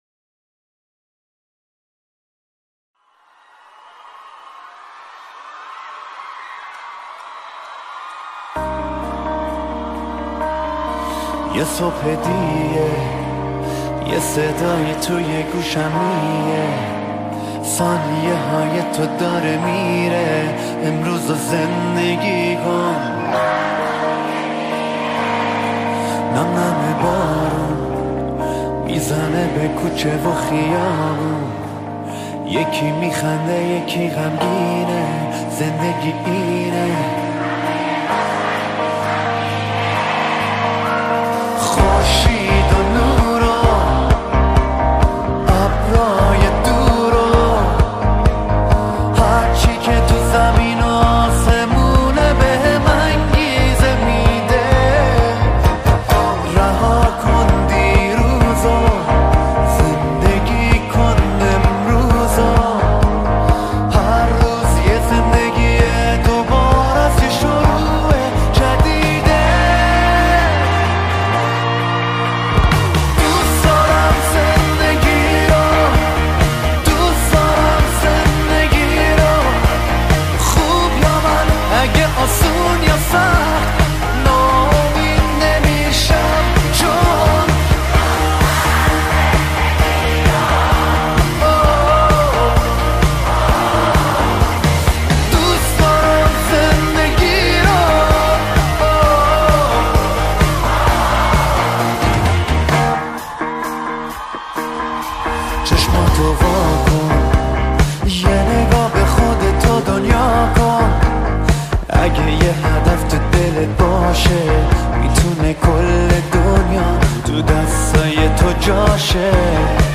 آهنگ شاد و بسیار زیبای